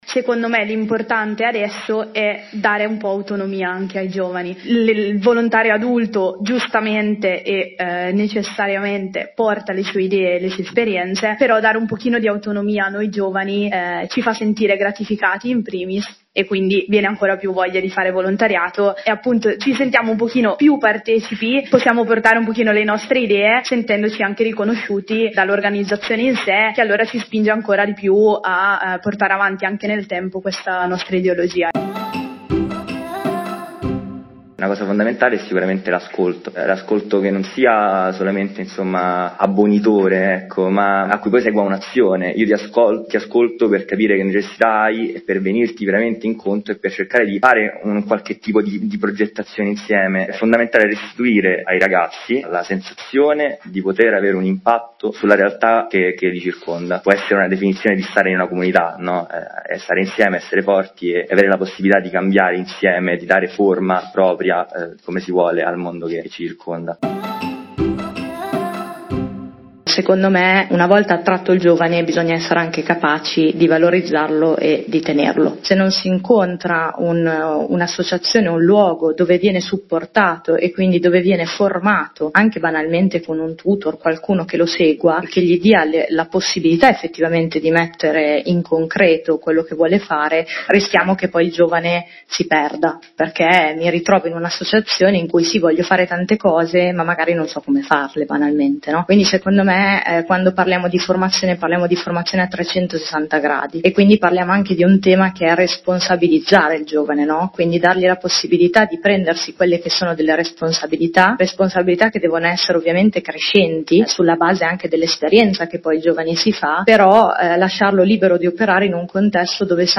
Come le organizzazioni possono diventare più accoglienti per le nuove generazioni? Ne hanno discusso alcuni giovani volontarie e volontari.